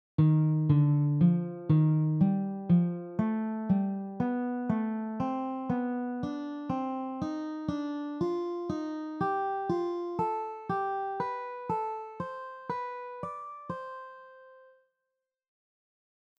The following patterns are all based on the C melodic minor scale.
Ascending 3rds scale pattern
For this scale pattern, we are playing intervals of thirds on each degree of the scale.